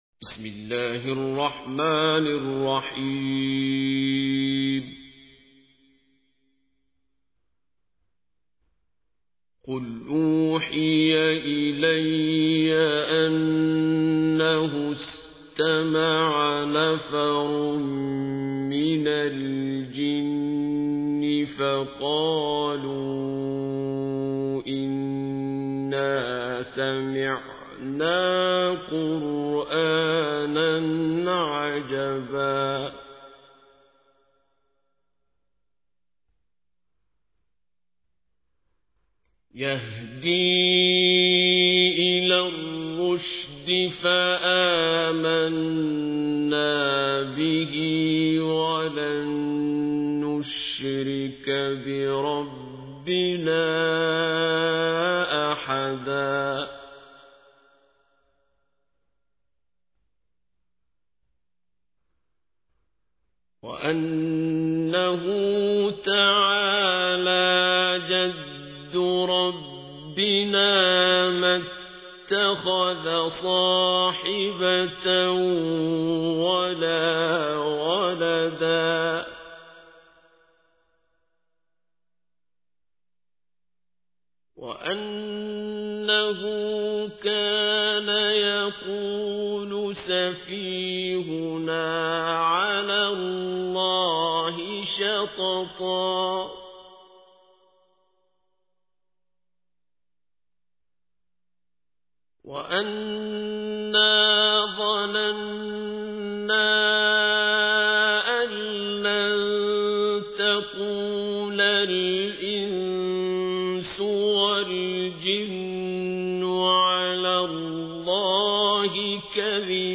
تحميل : 72. سورة الجن / القارئ عبد الباسط عبد الصمد / القرآن الكريم / موقع يا حسين